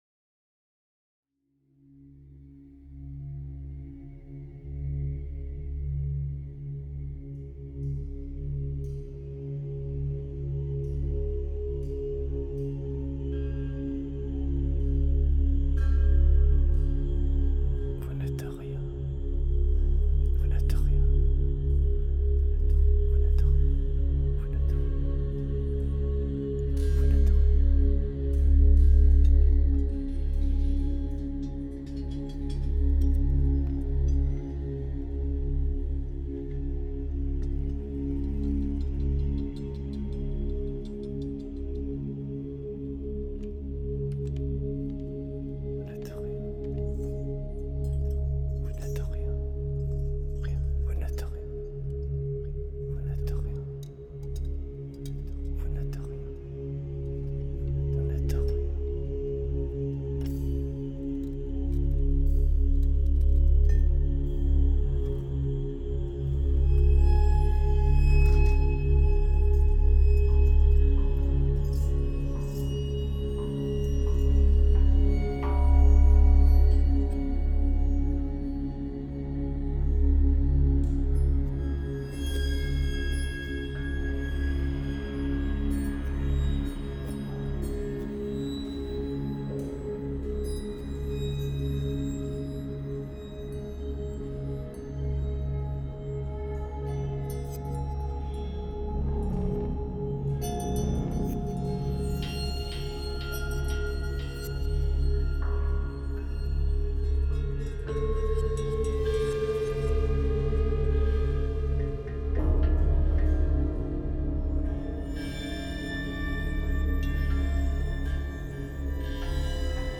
De Triomf Van De Dood - Composition pour: Quatre synthétiseurs, Cinq Échantillonneurs, Quatre pistes d’objets sonores, Des effets numériques...